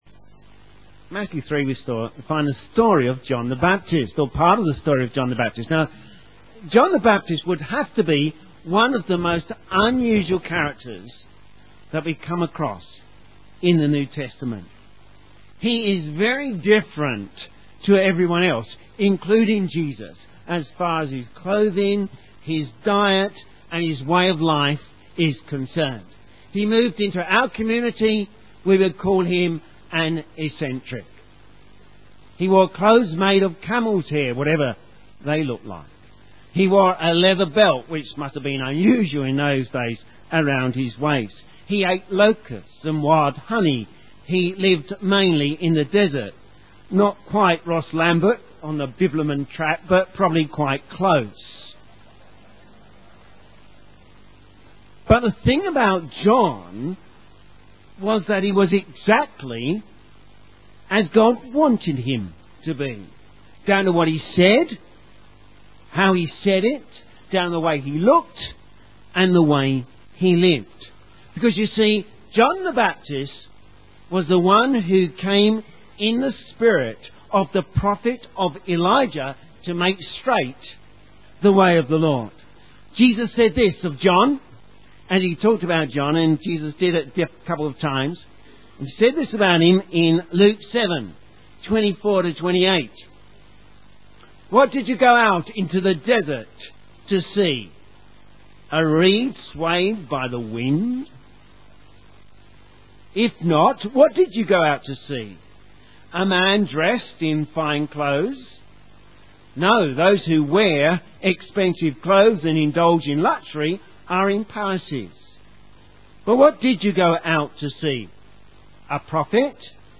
Sermon
BaptismalSermon-316.mp3